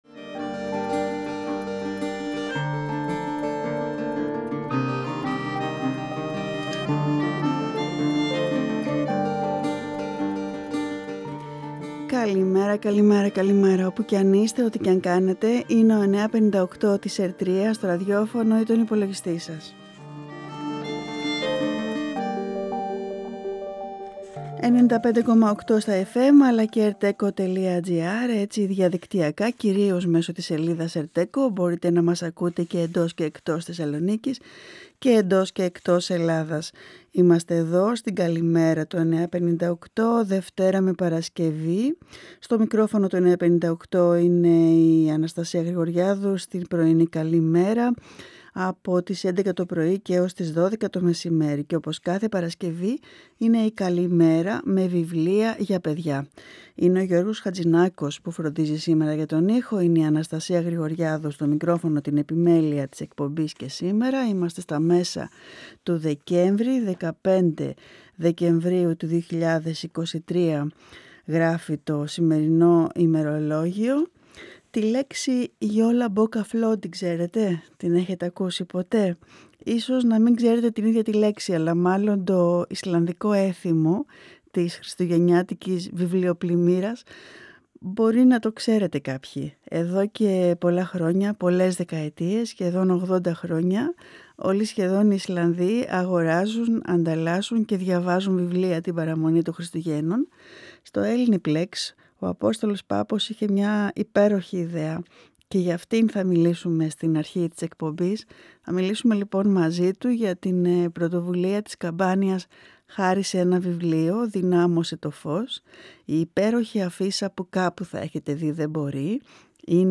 12/23 Εκπομπή “καλημέρα” στον 9,58fm της ΕΡΤ3